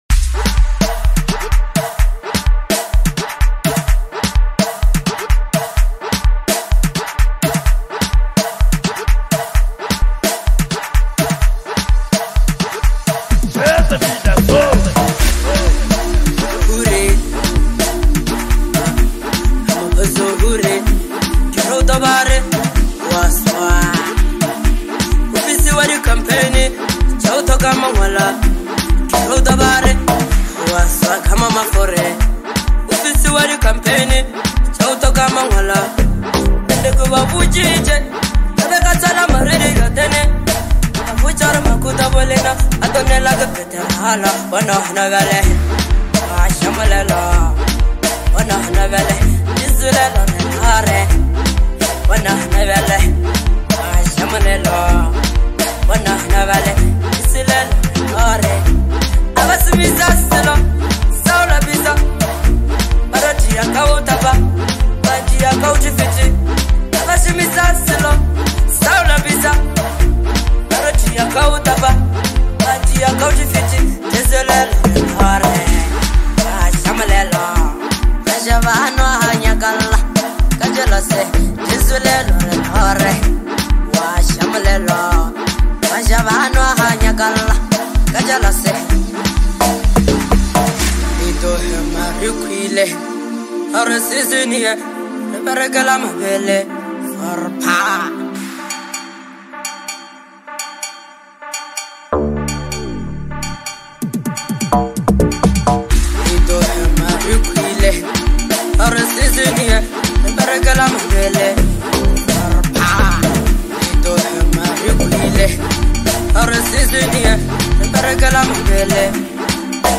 Bolohouse